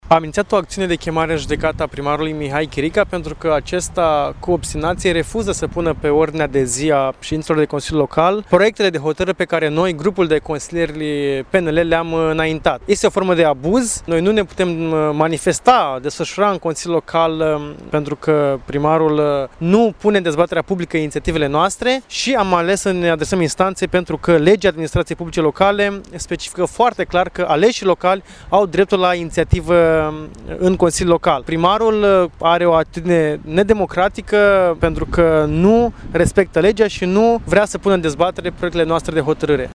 Consilierul local liberal, Răzvan Timofciuc, a precizat că niciun proiect de hotărâre supus dezbaterii publice şi ulterior aprobării în Consiliul Local nu a primit avizul necesar pentru a intra la vot: